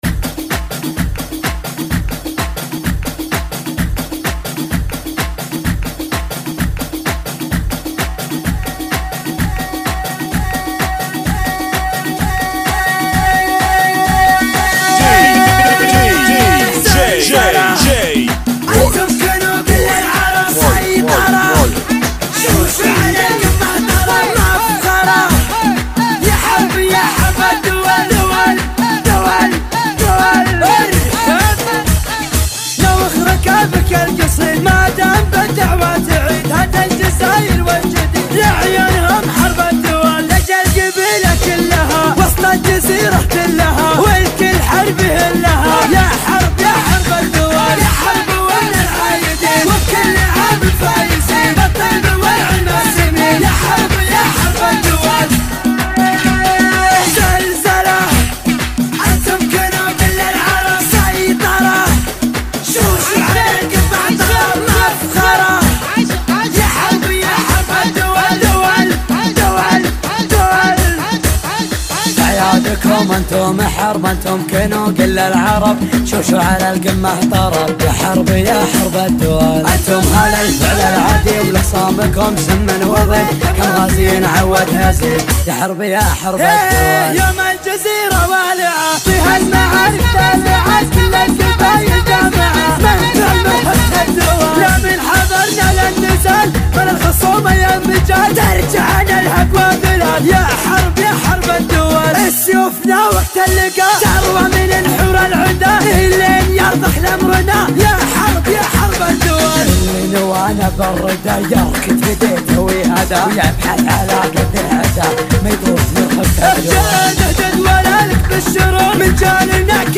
128 Bpm